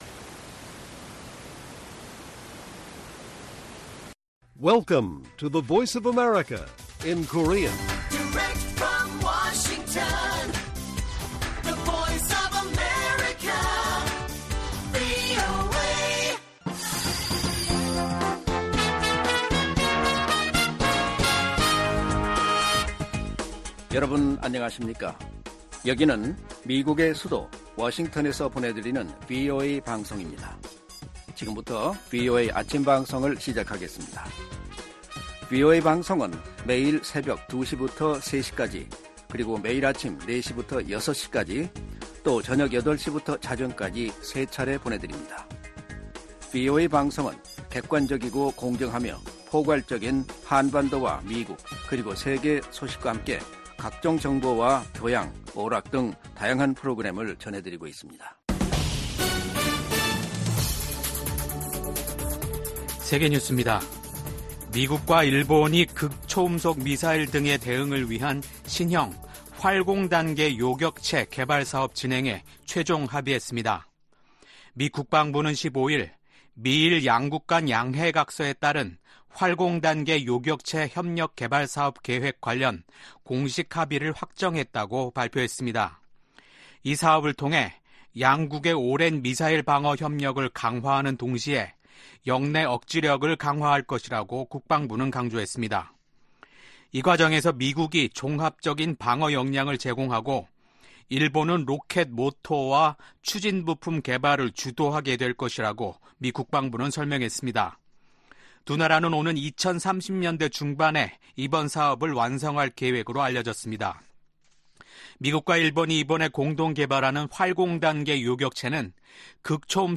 세계 뉴스와 함께 미국의 모든 것을 소개하는 '생방송 여기는 워싱턴입니다', 2024년 5월 17일 아침 방송입니다. '지구촌 오늘'에서는 블라디미르 푸틴 러시아 대통령과 시진핑 중국 국가주석이 베이징에서 정상회담을 하고 양국 간 포괄적인 전략적 협력 관계를 심화하기로 선언한 소식 전해드리고, '아메리카 나우'에서는 조 바이든 대통령과 도널드 트럼프 전 대통령이 오는 6월과 9월 두 차례 TV 토론을 갖기로 합의한 이야기 살펴보겠습니다.